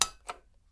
電化製品・電灯・スイッチ
壁スイッチ２
wall_switch2.mp3